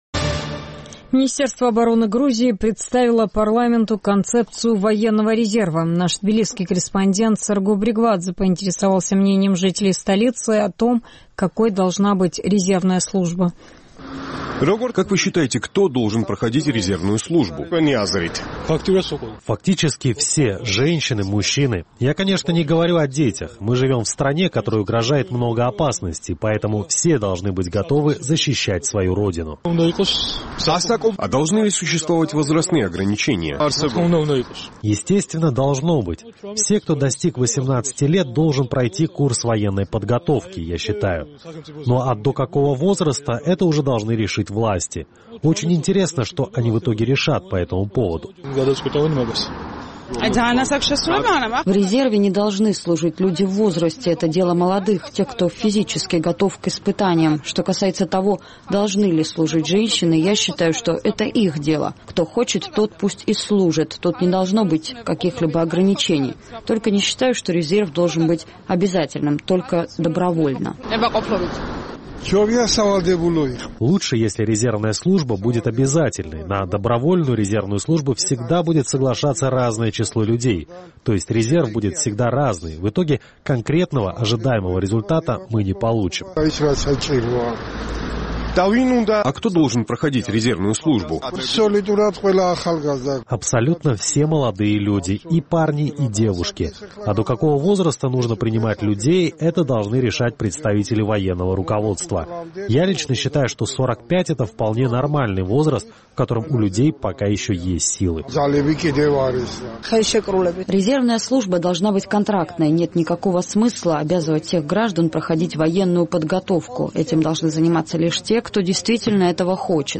Министерство обороны Грузии уже представило парламенту концепцию военного резерва. Наш тбилисский корреспондент поинтересовался мнением жителей столицы о том, какой должна быть резервная служба.